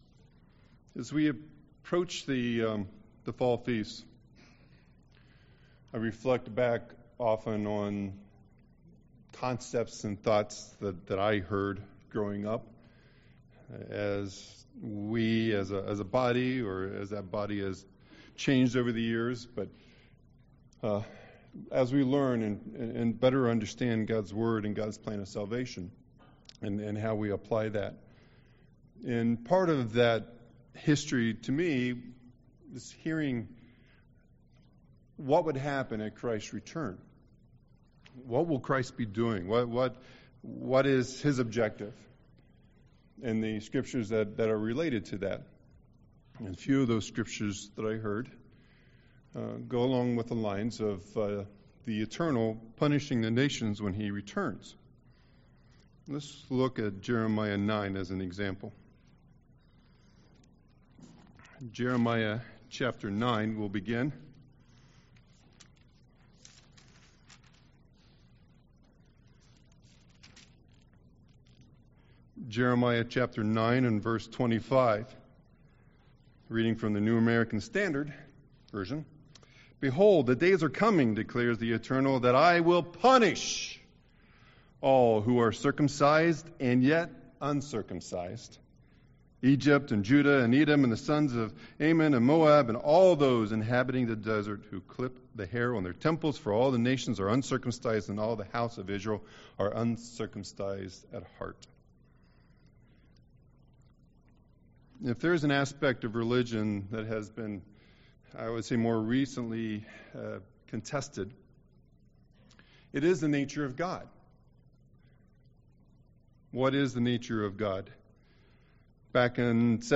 Given in Milwaukee, WI
UCG Sermon punishment punish people wrath king of kings Jesus translation Hebrew Studying the bible?